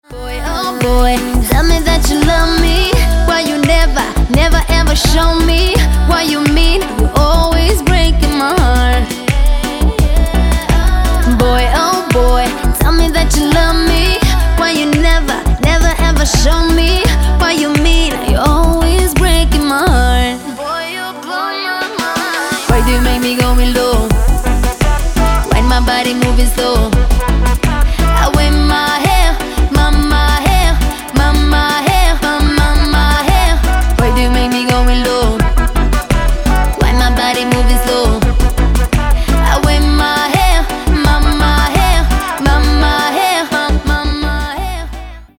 • Качество: 192, Stereo
поп
женский вокал
dance
vocal